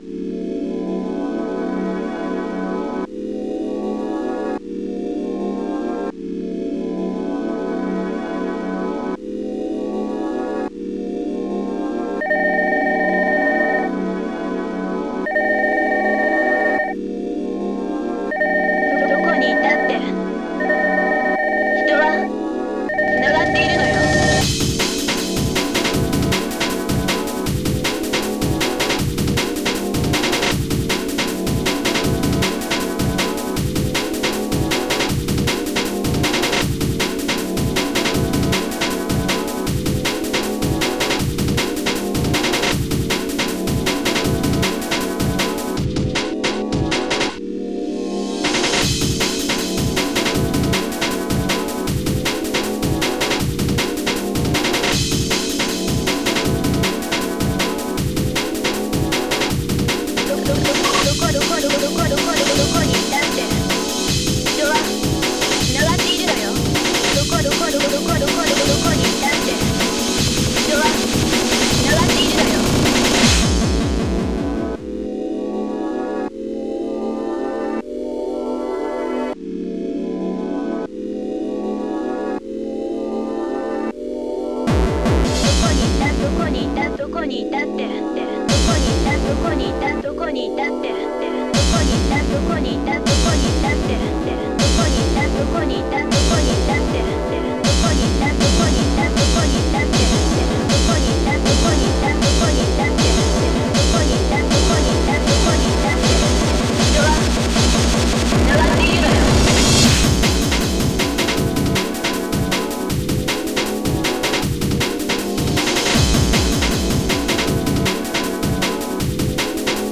全体的に、この曲はリラックスした雰囲気があり、
ジャングルのような雰囲気があります。
曲の終盤で予想外の展開が起こり、ノイジーなハードコア地獄に陥る ^_^ (笑)